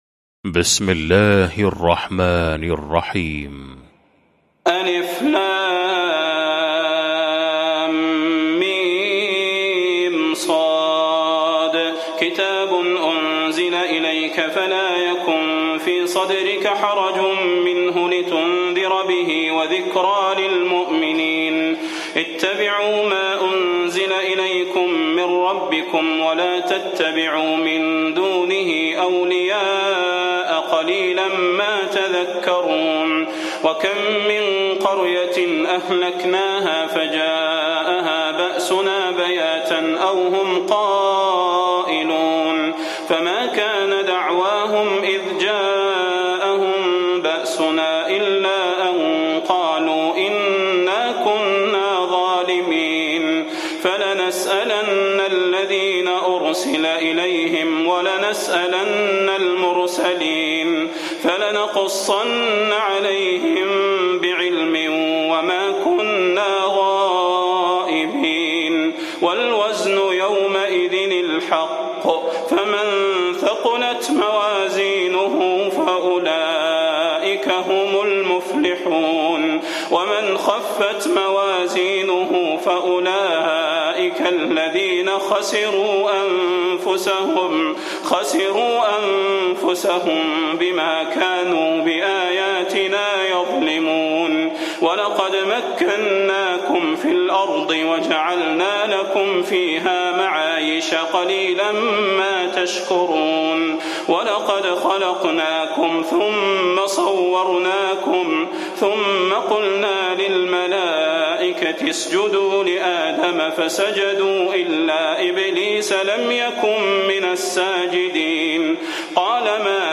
المكان: المسجد النبوي الشيخ: فضيلة الشيخ د. صلاح بن محمد البدير فضيلة الشيخ د. صلاح بن محمد البدير الأعراف